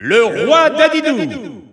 Announcer pronouncing King Dedede.
King_Dedede_French_Announcer_SSBU.wav